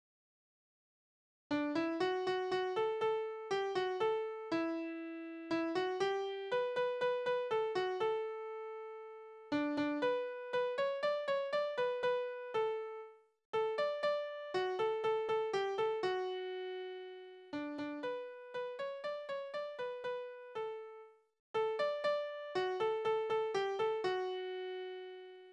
« O-10732 » Napoleon, du Schustergeselle. Historische Lieder: Napoleon Tonart: D-Dur Taktart: 4/4 Tonumfang: Oktave Besetzung: instrumental Externe Links